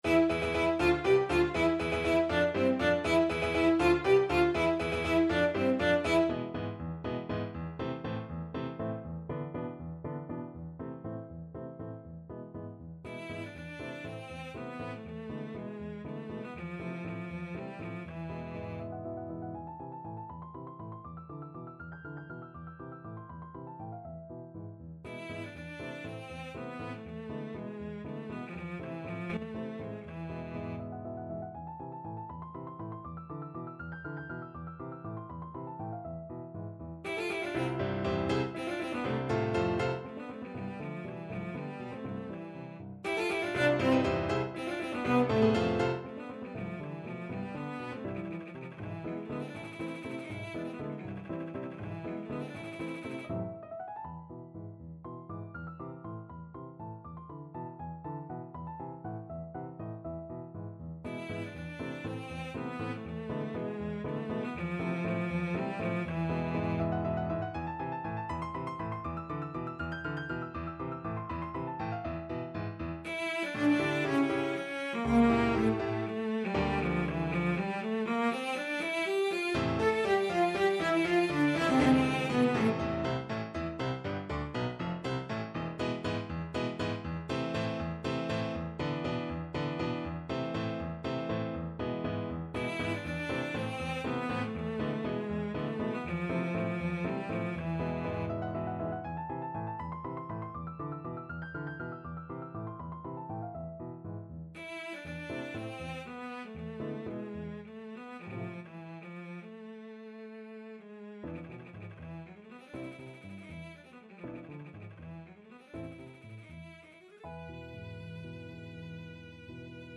Cello
3/8 (View more 3/8 Music)
A minor (Sounding Pitch) (View more A minor Music for Cello )
Allegro vivo (.=80) (View more music marked Allegro)
Classical (View more Classical Cello Music)